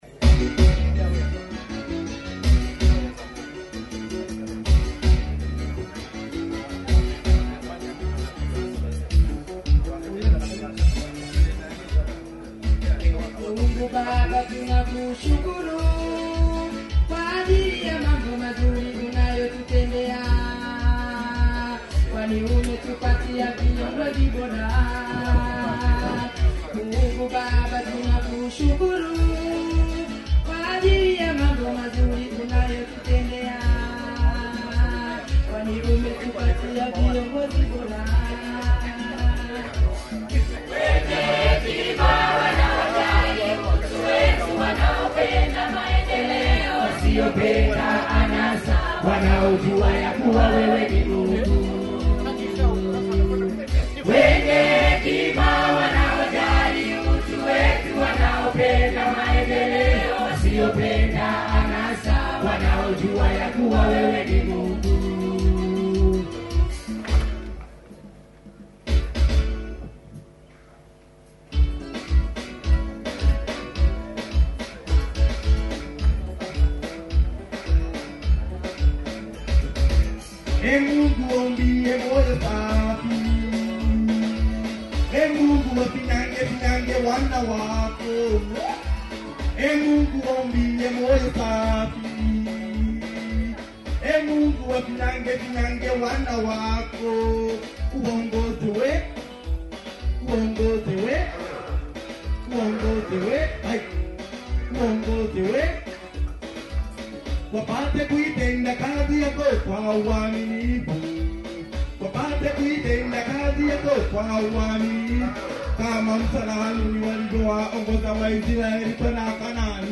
Kwaya 3
Kwaya3.mp3